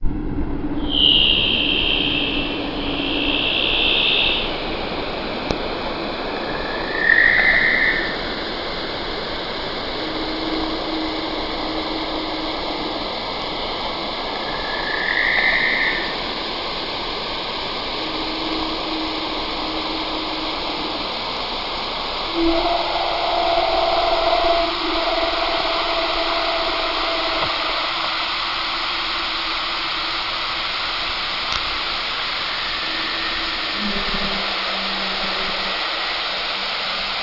На этой странице собраны звуки морских котиков — забавные и живые голоса этих удивительных животных.
Морской леопард хищник океана